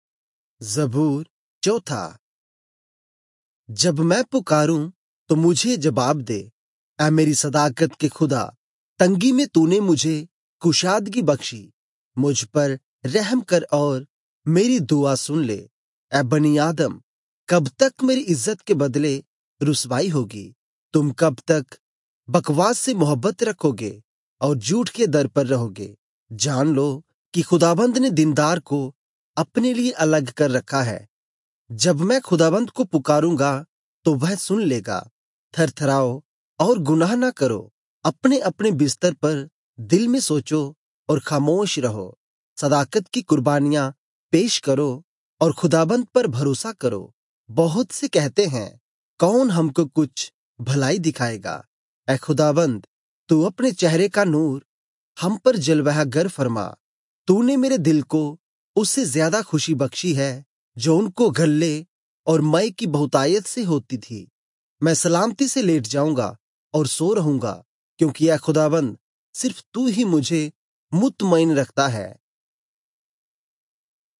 Urdu Audio Bible - Psalms 25 in Irvur bible version